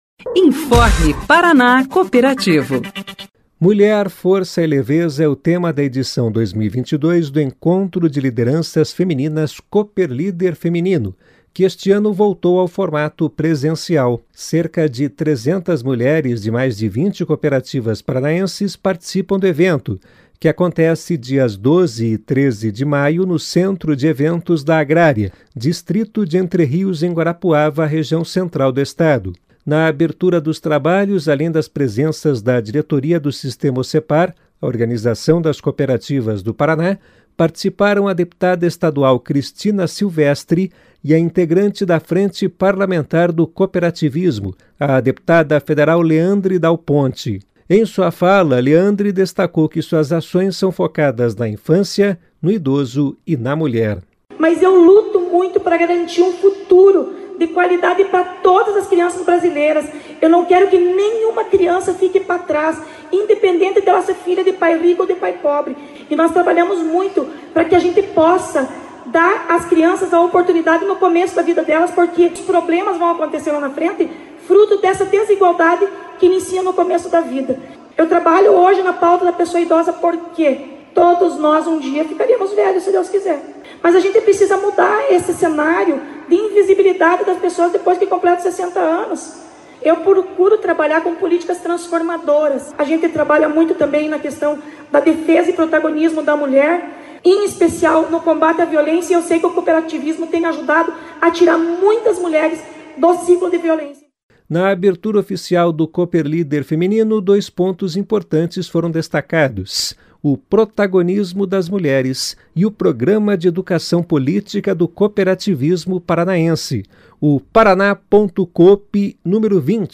Cerca de 300 mulheres de mais de 20 cooperativas paranaenses participaram do evento, realizado no Centro de Eventos da Agrária, Distrito de Entre Rios, em Guarapuava, Região Central do Estado.
Em sua fala, Leandre destacou que suas ações são focadas na infância, no idoso e na mulher.